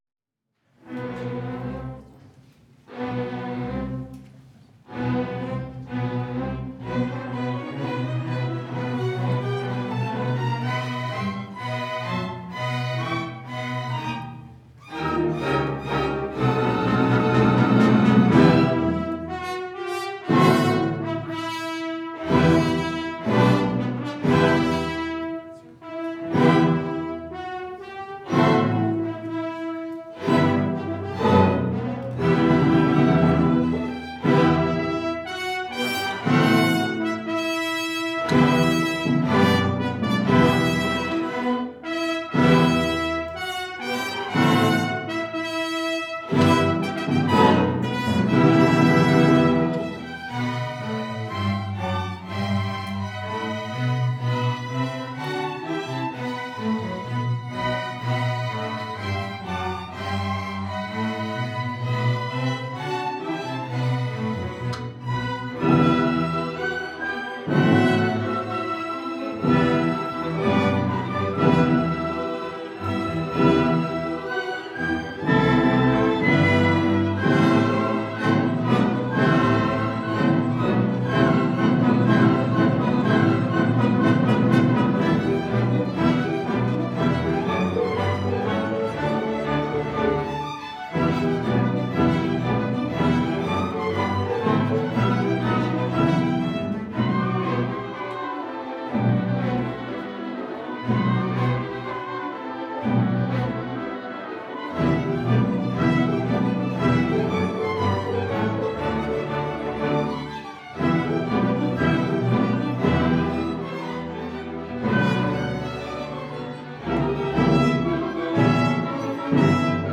Second String Orchestra
Spring Concert